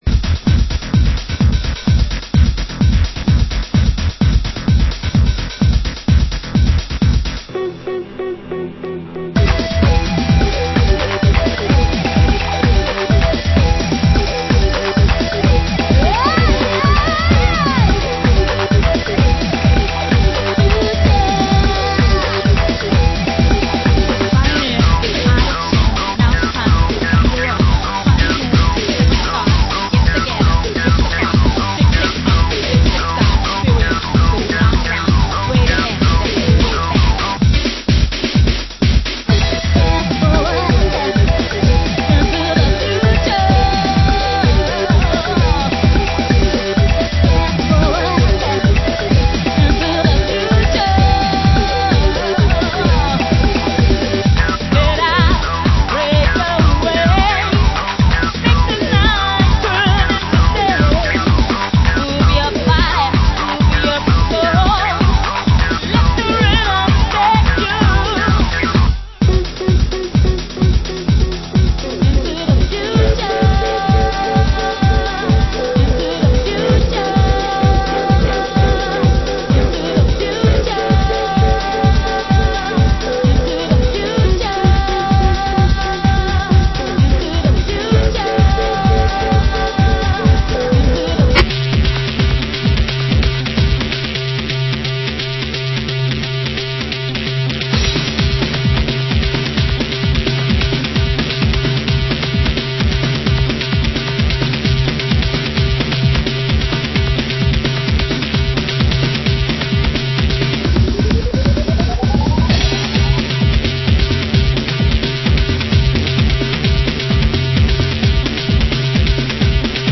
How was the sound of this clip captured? Format: Vinyl 12 Inch